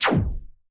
sounds_laser_02.ogg